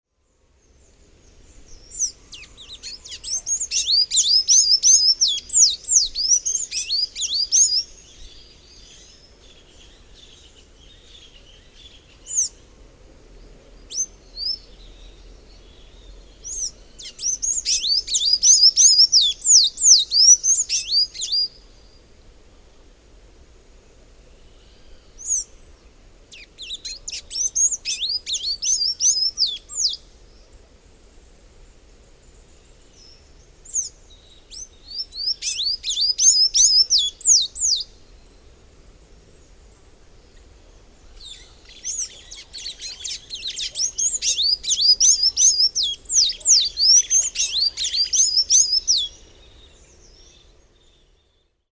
Sanhaço-cinzento
É uma ave que canta bastante: um canto agradável e variado, com notas bem agudas.
Aprecie o canto do
sanhacocinzento.mp3